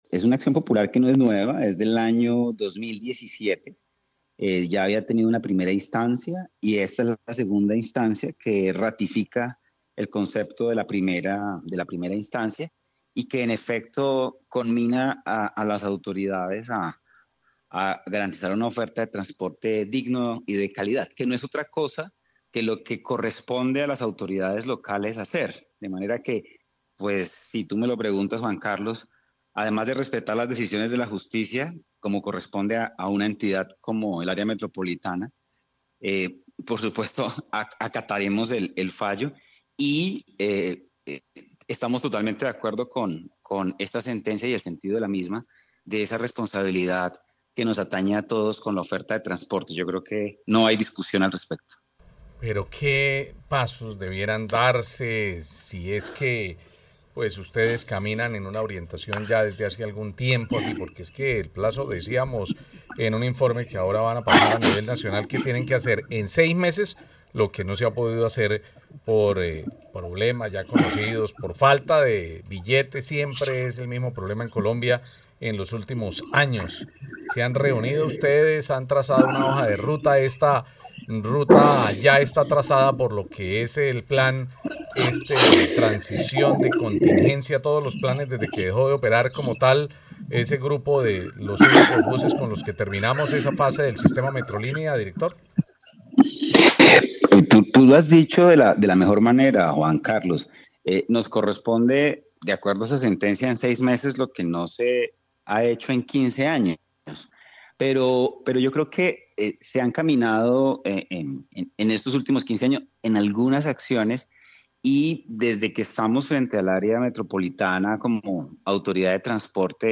Jhon Manuel Delgado, director del área metropolitana de Bucaramanga
Así lo confirmó el director de la entidad, Jhon Manuel Delgado, en diálogo con Caracol Radio, en la que ratificó el compromiso de las autoridades locales.